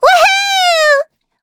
Taily-Vox_Happy4.wav